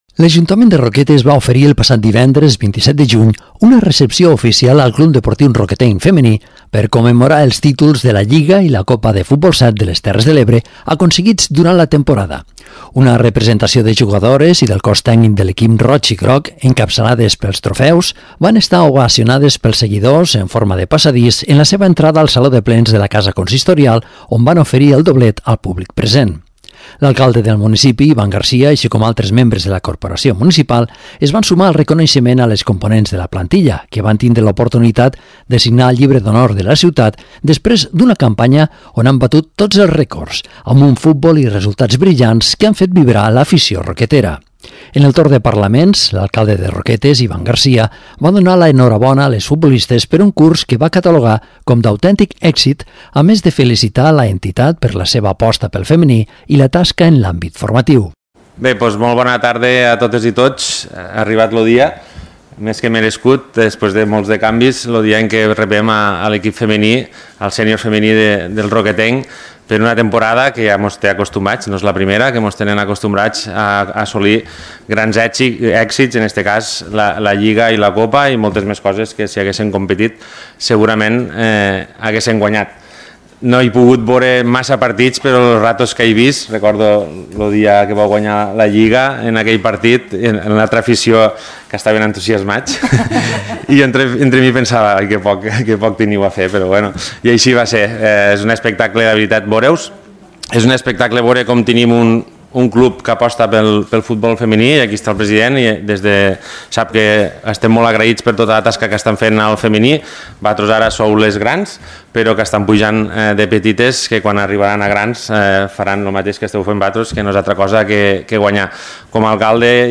L’Ajuntament de Roquetes ha ofert aquest divendres una recepció oficial al CD Roquetenc Femení, per commemorar els títols de la lliga i la copa de futbol-7 de les Terres de l’Ebre aconseguits durant la temporada. Una representació de jugadores i del cos tècnic de l’equip roig-i-groc, encapçalades pels trofeus, han estat ovacionades pels seguidors en forma de passadís en la seva entrada al Saló de Plens de la casa consistorial, on han ofert el doblet al públic present.